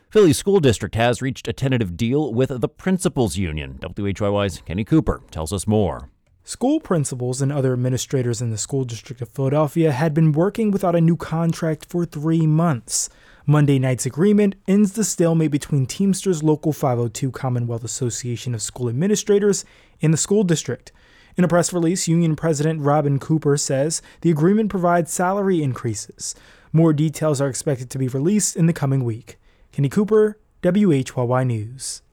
Philadelphia District Attorney Seth Williams announces charges against three current and former state lawmakers from Philadelphia.